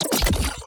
Reloading_finish0003.ogg